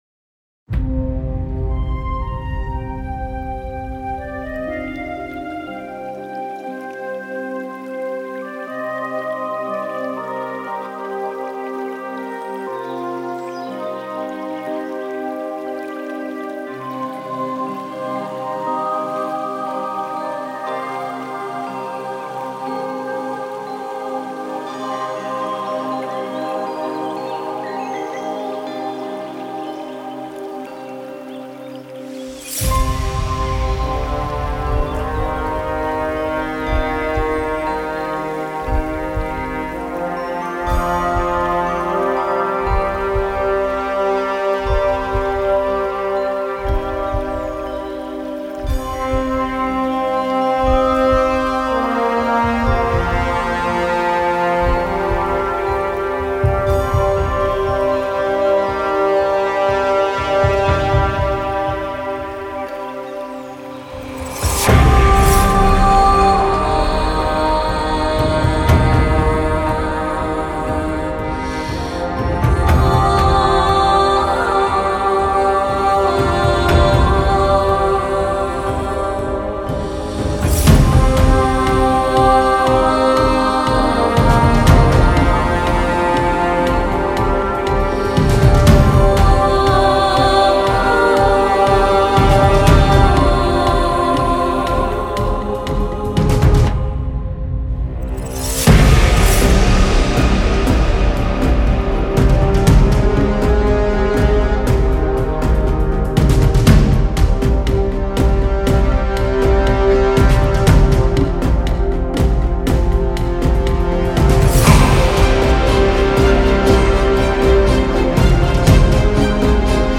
多才多艺的电影人声——从维京人到空灵
31 个乐器循环（钟声、铜管乐器、合唱团、钢琴、弦乐、木管乐器）